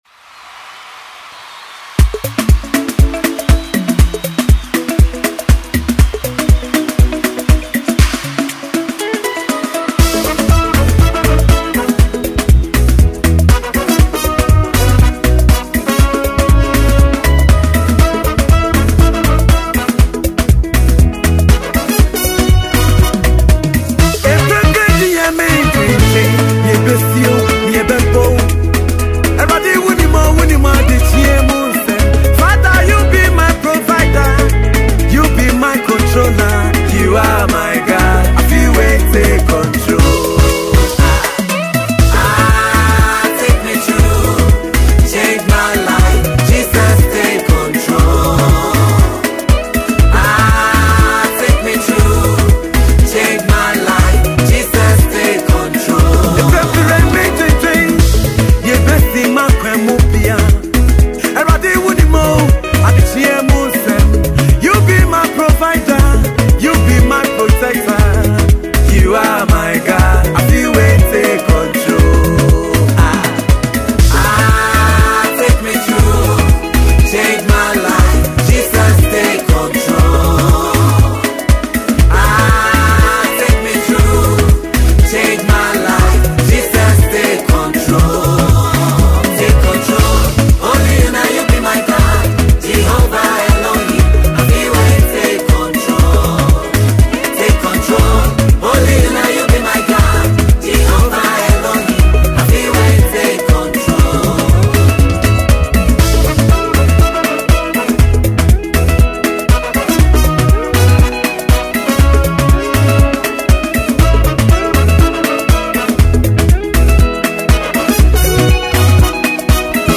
And gospel music minister